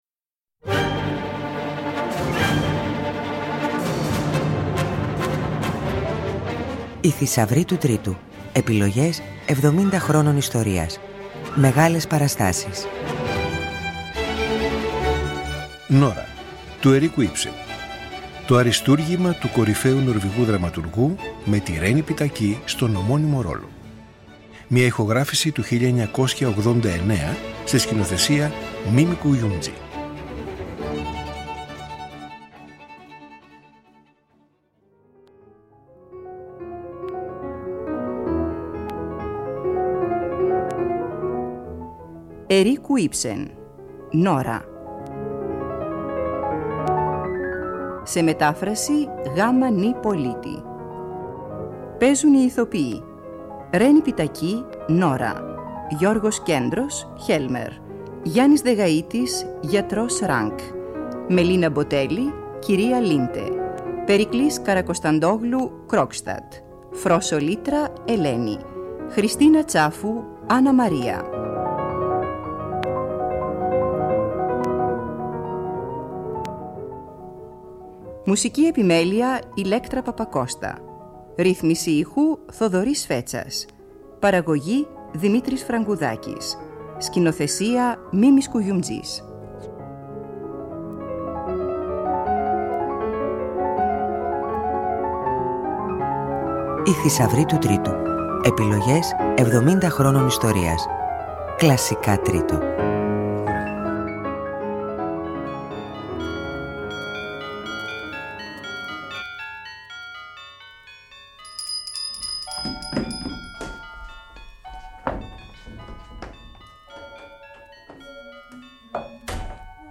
Ακούστε στην εκπομπή της Πέμπτης 04-07-24 μία από τις «Μοναδικές παραστάσεις» που μας μεταφέρουν στη χρυσή εποχή του Θεάτρου Τέχνης, του μοναδικού αυτού πυρήνα πολιτισμού που είχε ιδρύσει ο Κάρολος Κουν, με δύο από τους πλέον εμβληματικούς εκπροσώπους του: ο Μίμης Κουγιουμτζής σκηνοθετεί και η Ρένη Πιττακή πρωταγωνιστεί στην ιστορική ηχογράφηση της «Νόρας» του Ίψεν.